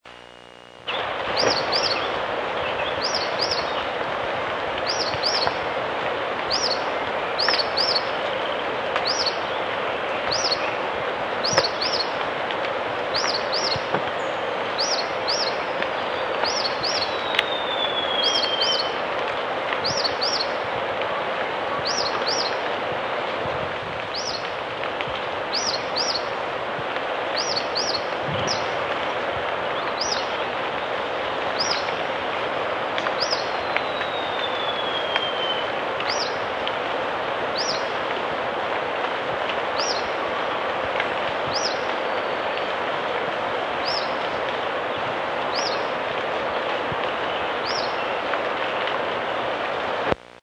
Mosqueta Pico Curvo Fiofío Plomizo
mosquepicocur.mp3